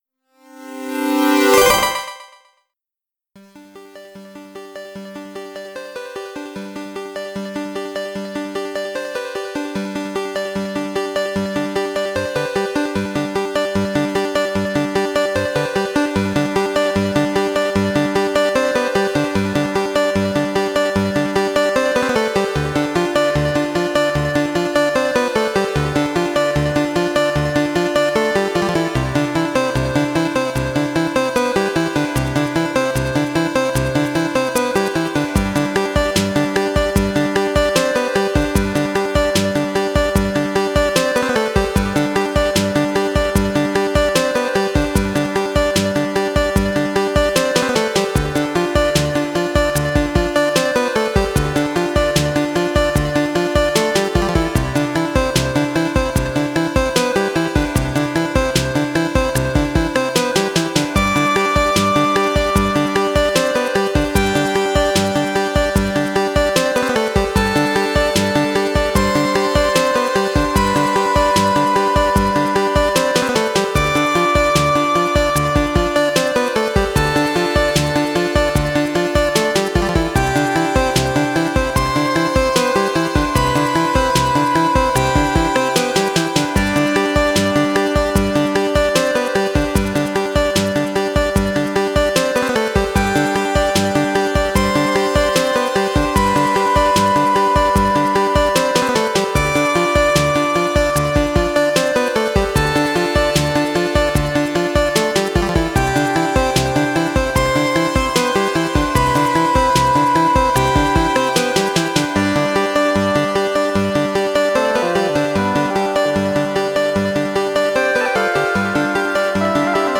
Ambient electronic sound